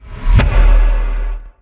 AirplaneStarts.wav